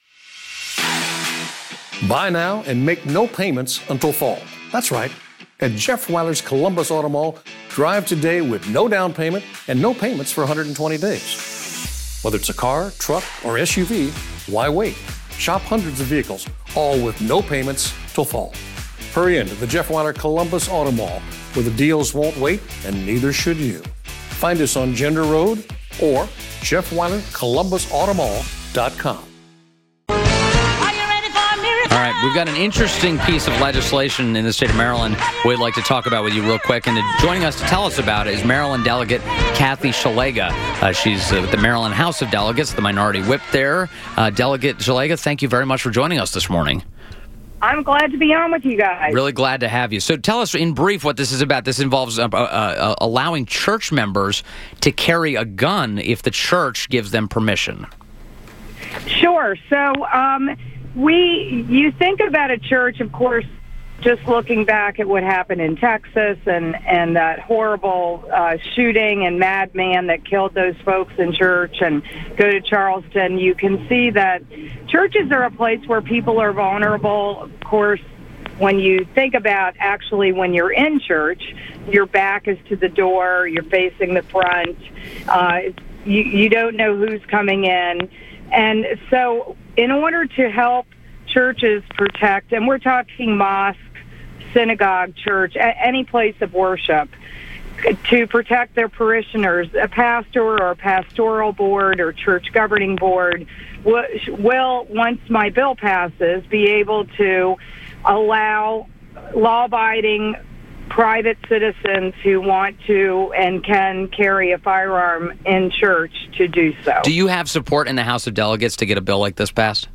INTERVIEW - MD DELEGATE KATHY SZELIGA (SH-LEG_GAH) - Maryland House of Delegates Minority Whip